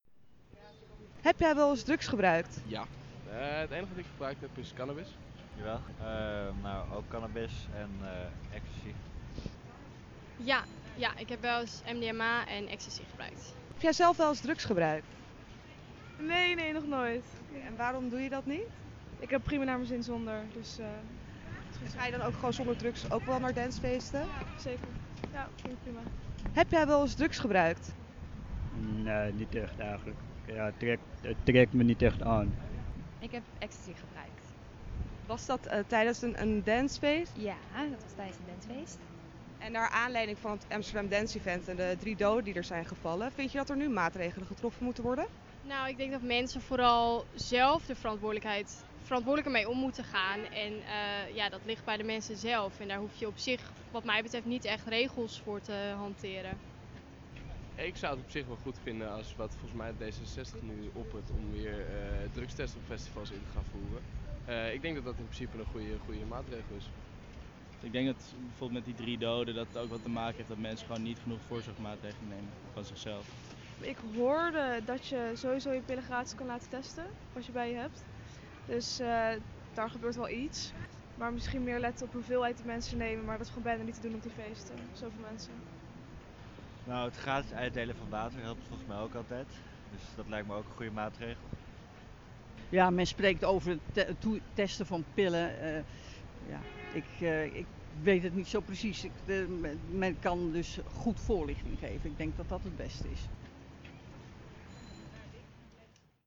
Maar welke maatregelen zien Amsterdammers het liefst? AmsterdamFM ging de straat op om het ze te vragen.